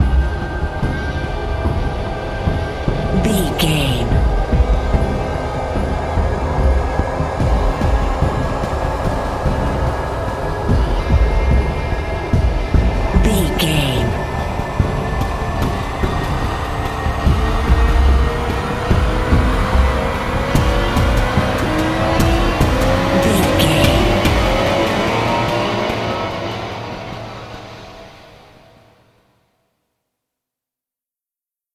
Thriller
Aeolian/Minor
synthesiser
drum machine